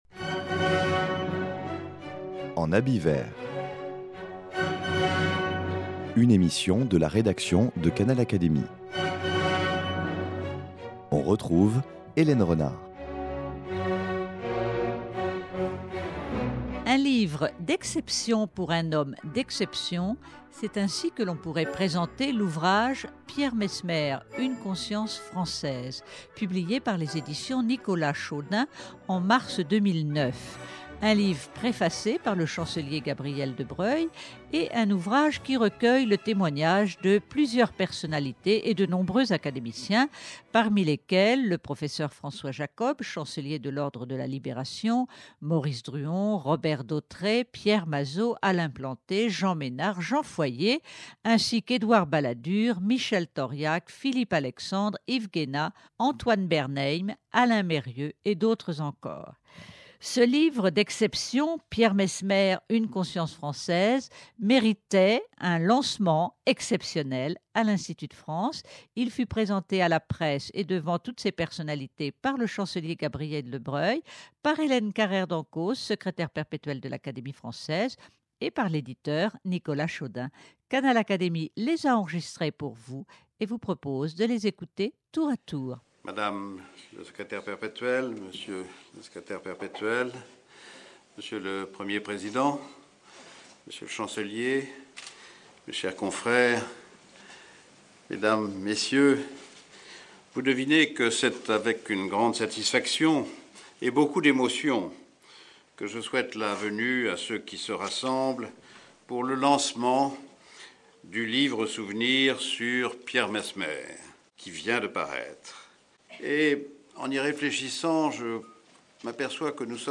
L’ouvrage Pierre Messmer, une conscience française méritait bien un lancement exceptionnel à l’Institut de France.